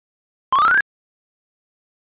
Download Remote Control sound effect for free.